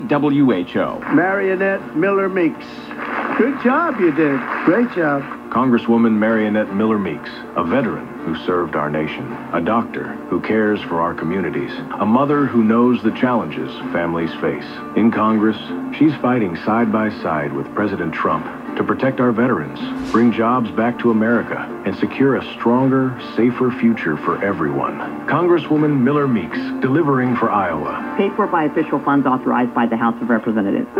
The day after this spot stopped running on Facebook and Instagram, an audio version with the same script popped up on some Iowa radio stations.
There was also a radio version of “Delivering for Iowa,” which began running on December 2 on WHO Radio in Des Moines, an influential conservative AM station.
The invoices don’t indicate which script is on the air, but the only spot I’ve heard on the station is the one beginning with Trump’s voice: “Mariannette Miller-Meeks. Good job you did! Great job.”